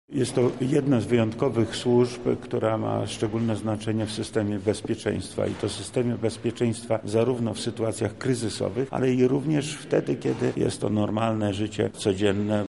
W Urzędzie Wojewódzkim odbył się uroczysty apel.
-mówi wojewoda lubelski Lech Sprawka.